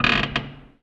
metal_low_creak_squeak_08.wav